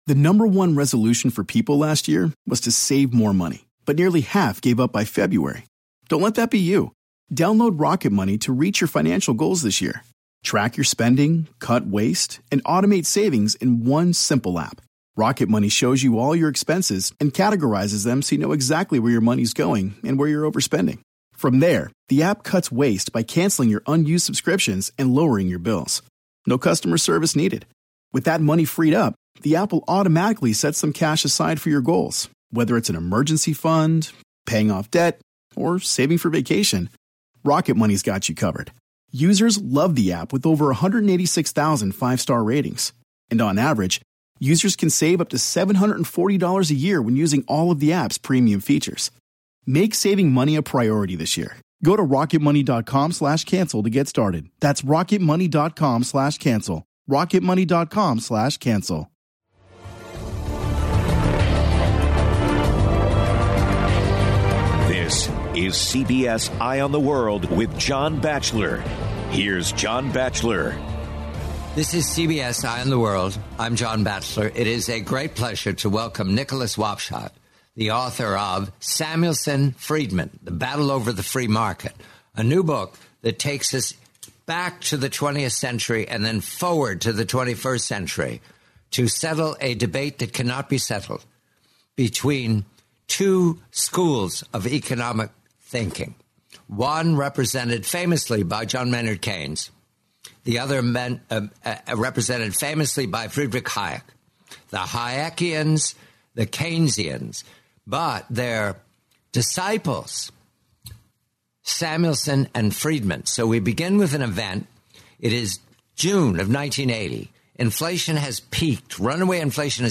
Samuelson Friedman: The Battle Over the Free Market. The complete forty-minute interview, July 24, 2016.